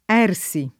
ergere [$rJere] v.; ergo [$rgo], ‑gi — pass. rem. ersi [